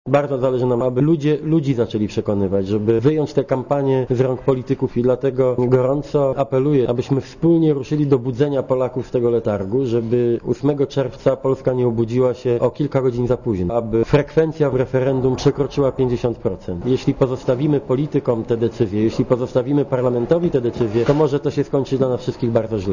Mówi Donald Tusk (90Kb)